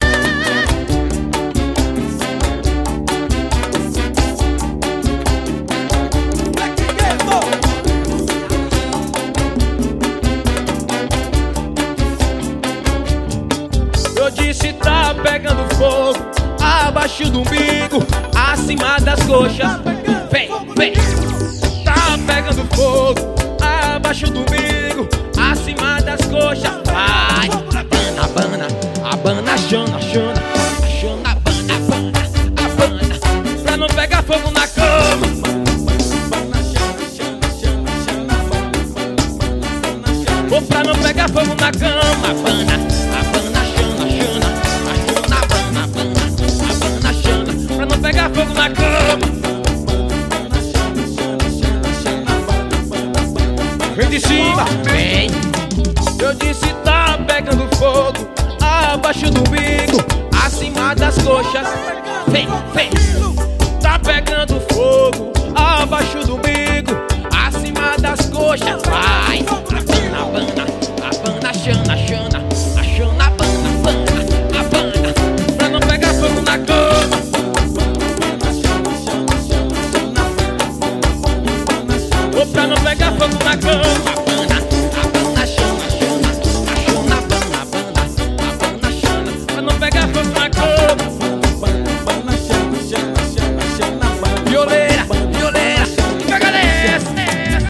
pagodão.